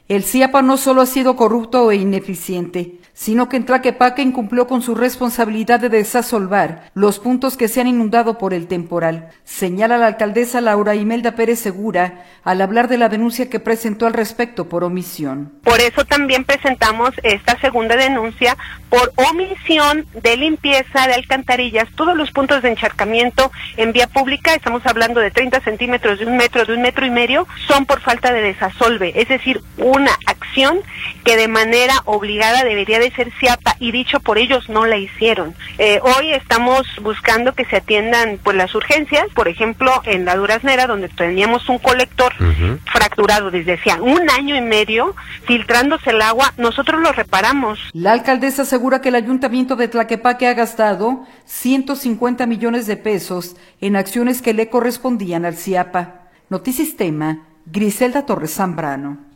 El SIAPA no solo ha sido corrupto e ineficiente, sino que en Tlaquepaque incumplió con su responsabilidad de desasolvar los puntos que se han inundado por el temporal, señala la alcaldesa, Laura Imelda Pérez Segura, al hablar de la denuncia que presentó al respecto por omisión.